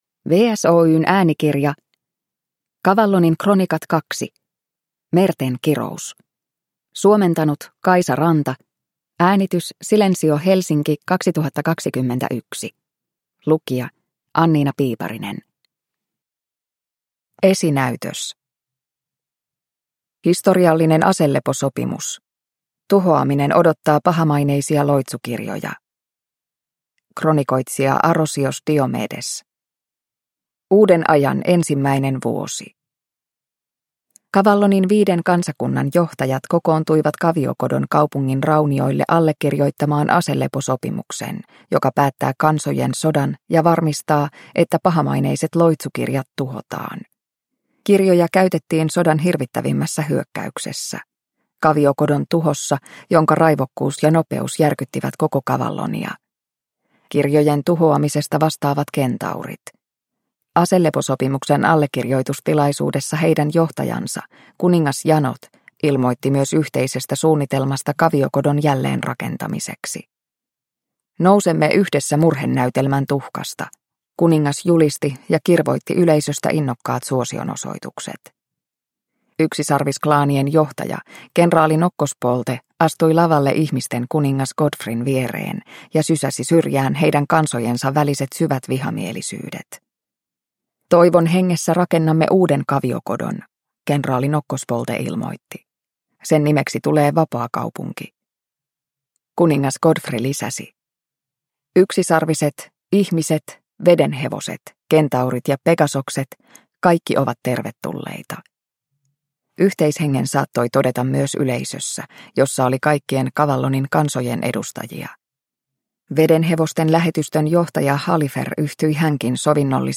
Cavallonin kronikat 2: Merten kirous – Ljudbok – Laddas ner